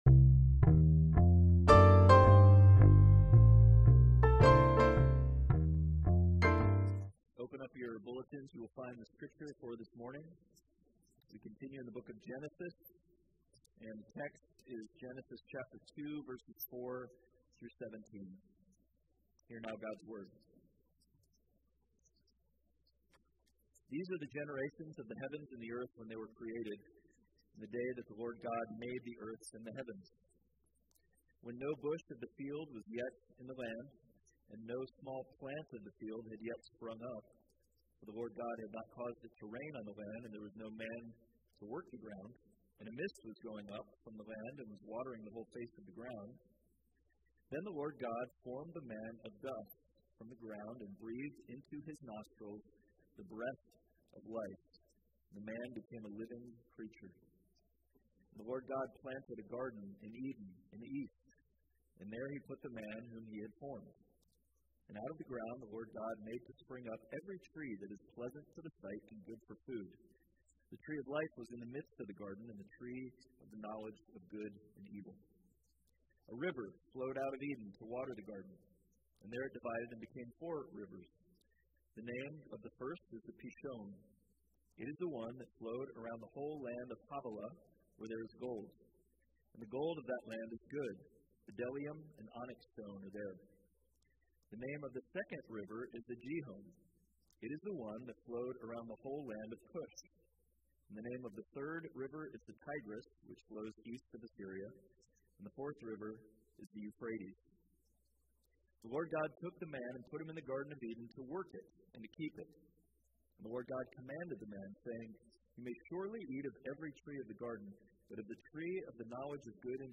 Passage: Genesis 2:4-17 Service Type: Sunday Worship